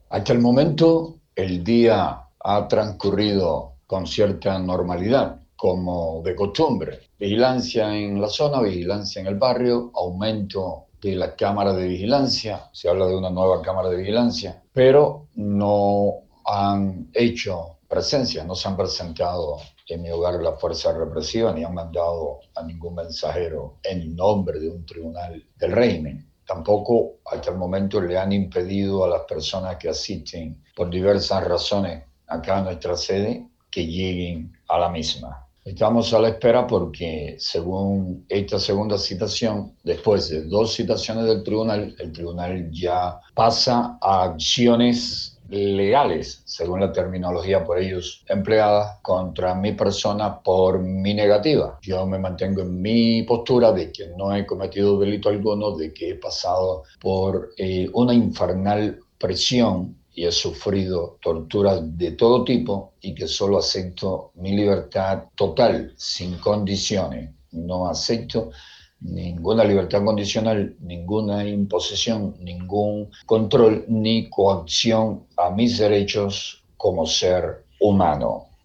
Declaración de José Daniel Ferrer